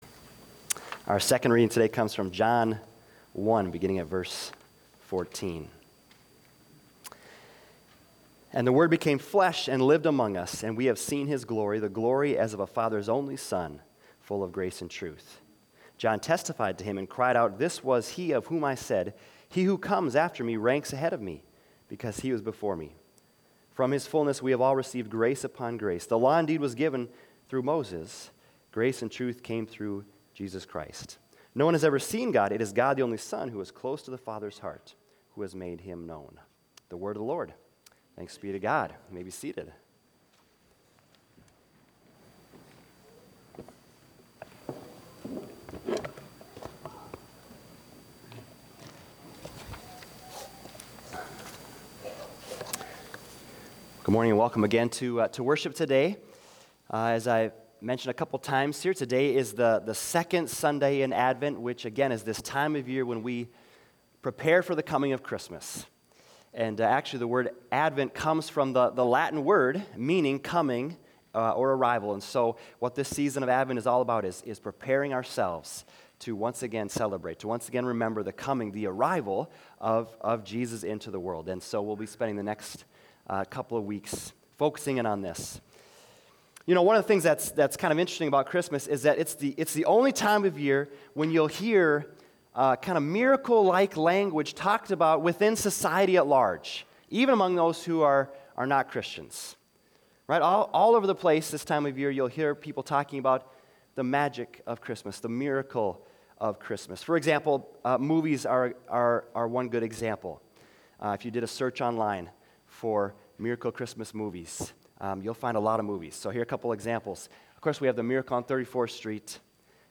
Sermons 2025 - Bethesda Lutheran Church